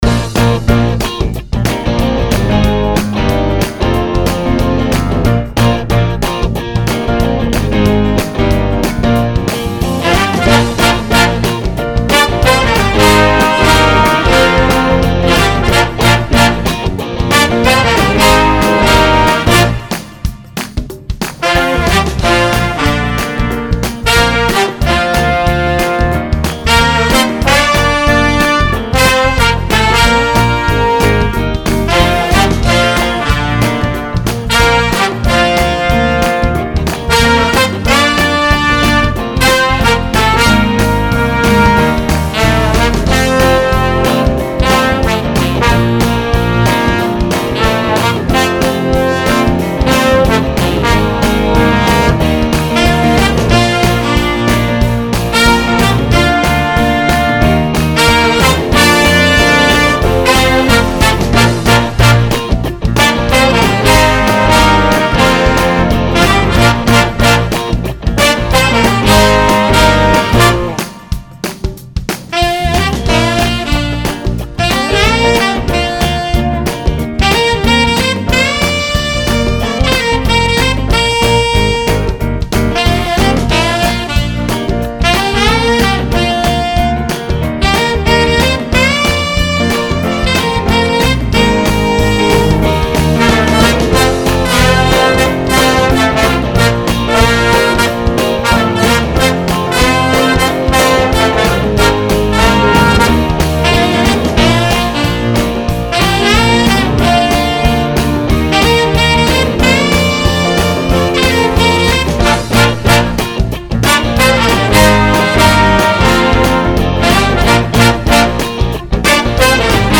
For now, here are some rough mix samples.